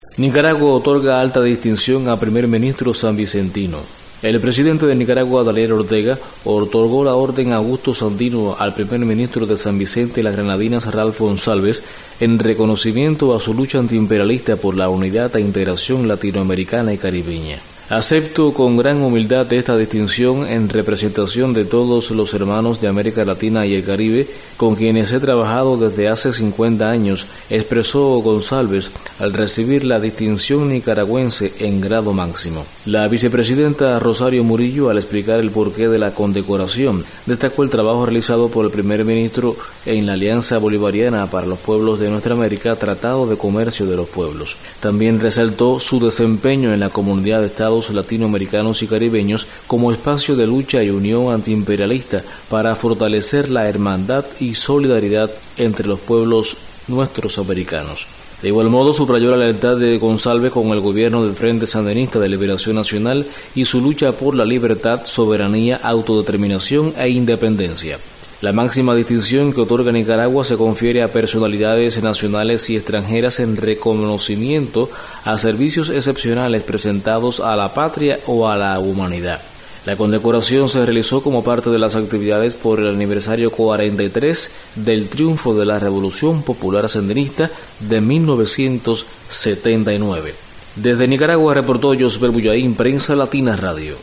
desde Managua